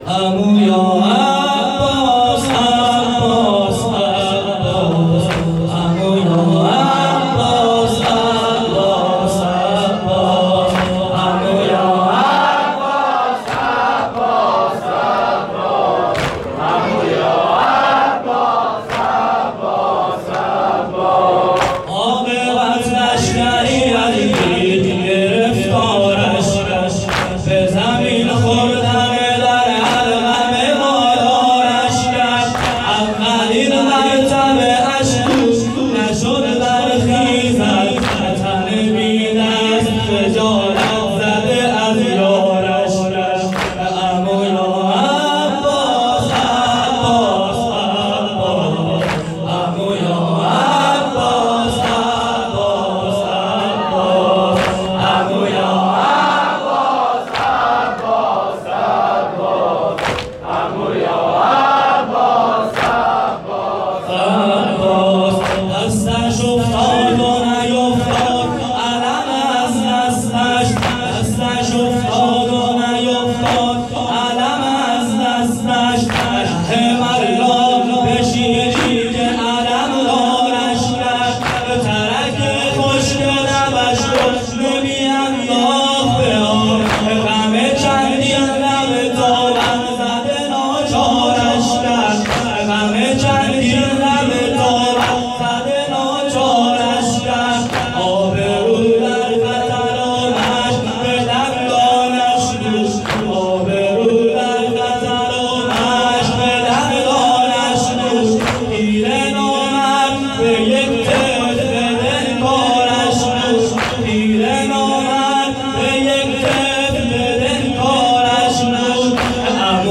شب تاسوعا محرم97 هیئت میثاق الحسین(ع)
فایلهای پائین کیفیت موبایل میباشد